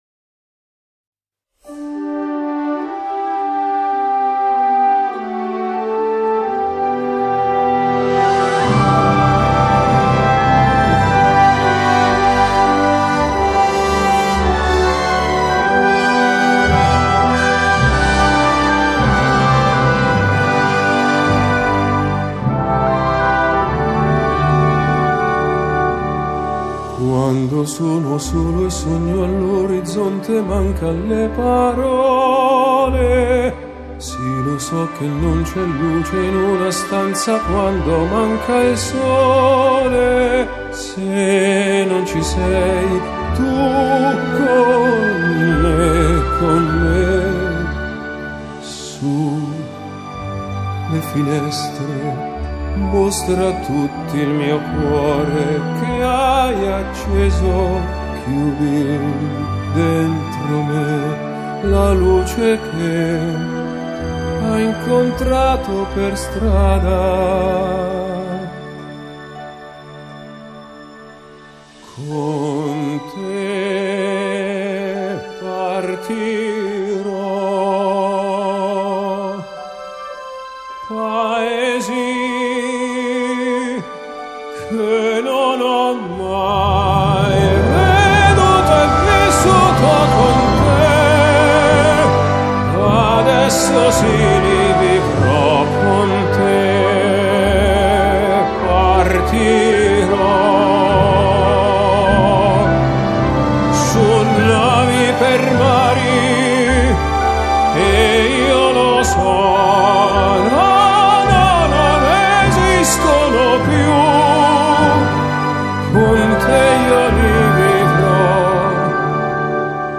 Orchestra and Choir Version